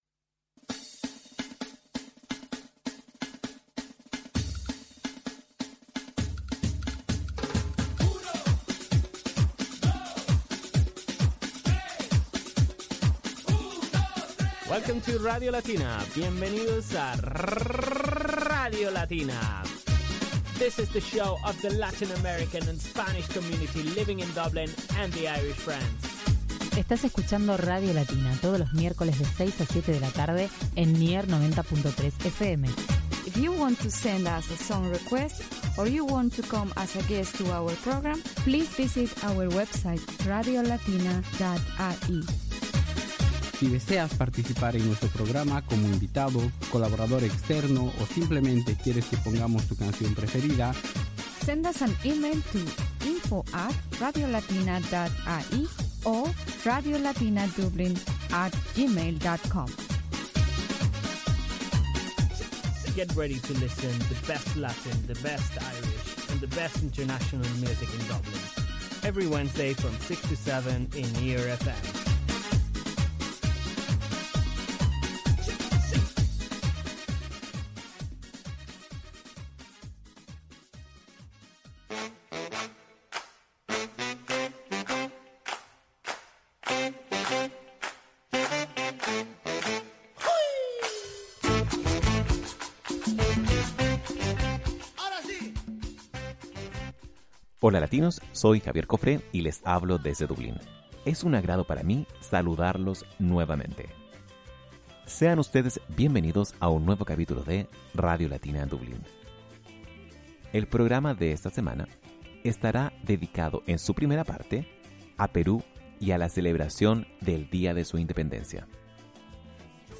Fiestas Peruanas y una entrevista